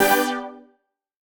Index of /musicradar/future-rave-samples/Poly Chord Hits/Straight
FR_PHET[hit]-G.wav